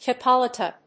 音節chip・o・la・ta 発音記号・読み方
/tʃìpəlάːṭə(米国英語)/